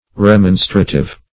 Remonstrative \Re*mon"stra*tive\ (r?*m?n"str?*t?v), a.